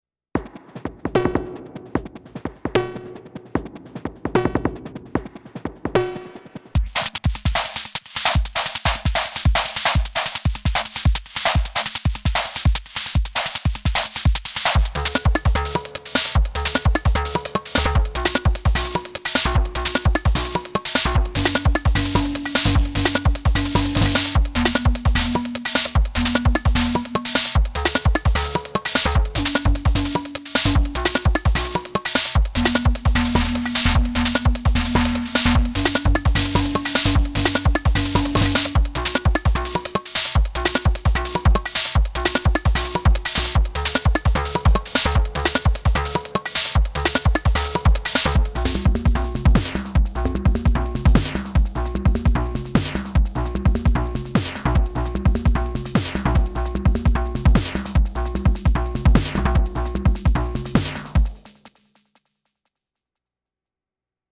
I love the “crackliness” of the SD Basic.
A wild Ctrl-all drum thing with short delay times and a lot of FX drive. 7 tracks of SDB.